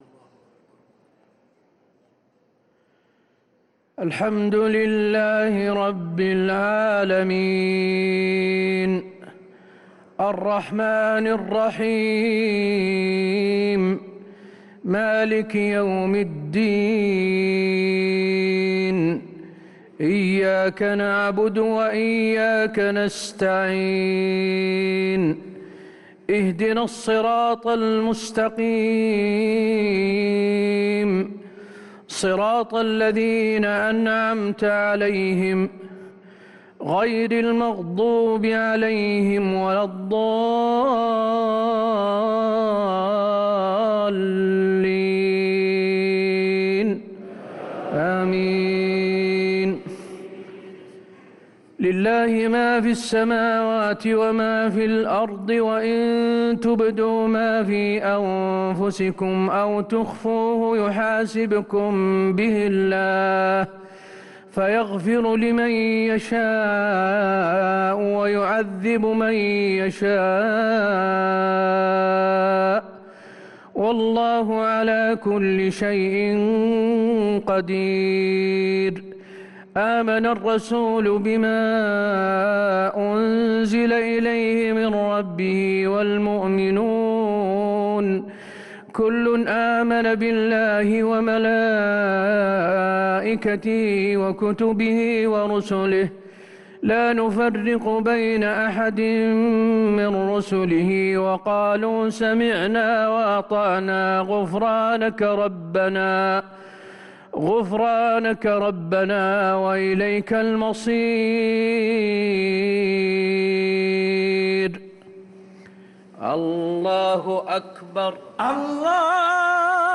عشاء الثلاثاء 13 رمضان 1444هـ خواتيم سورة البقرة | Isha prayer from Surah Al-Baqarah 4-4-2023 > 1444 🕌 > الفروض - تلاوات الحرمين